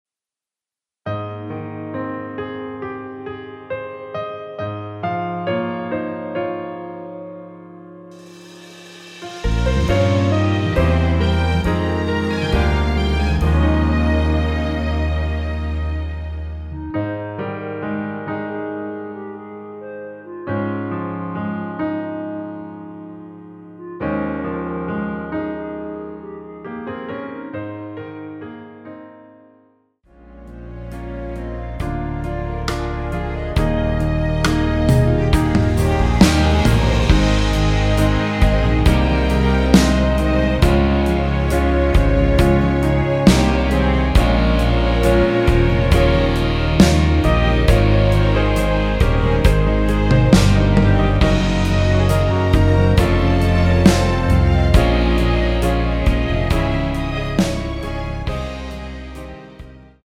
(-3)내린 멜로디 포함된 MR 입니다.(미리듣기 참조)
Ab
멜로디 MR이라고 합니다.
앞부분30초, 뒷부분30초씩 편집해서 올려 드리고 있습니다.